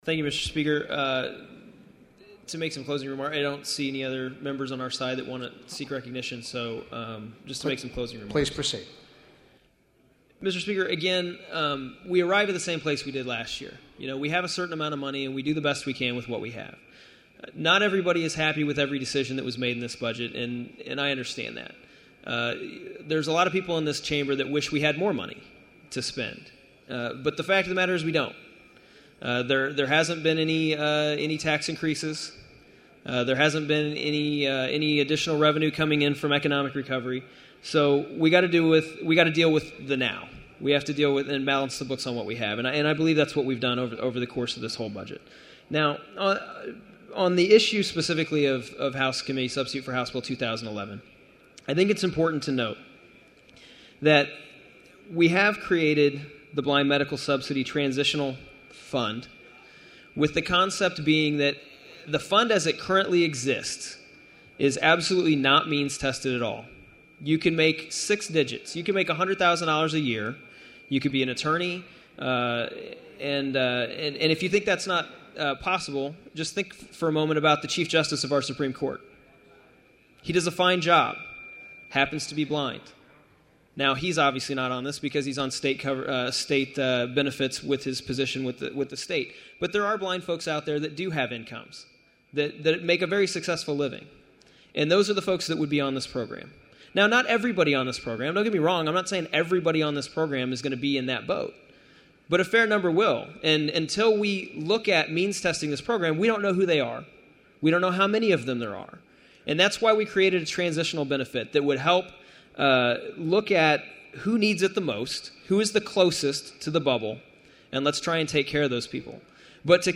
AUDIO:  Budget Committee Chairman Ryan Silvey’s statement closing budget debate on the House Floor, 6:05